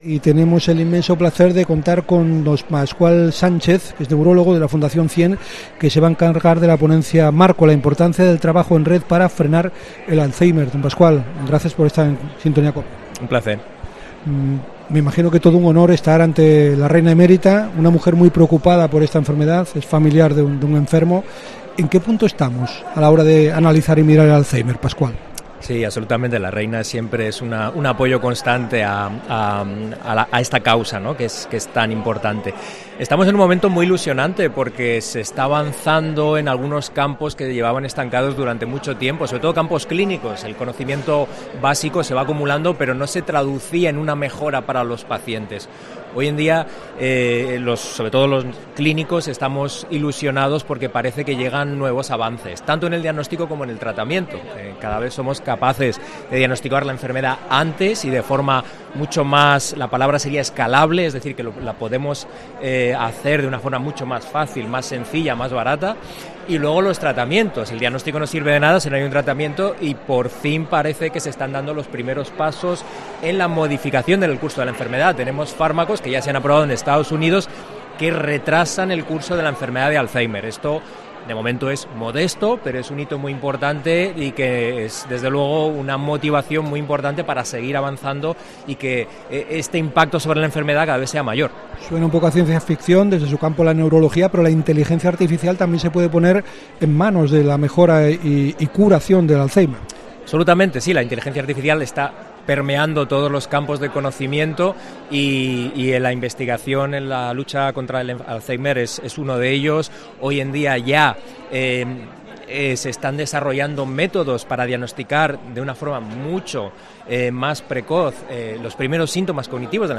habla, en COPE, de los retos en la lucha contra el Alzheimer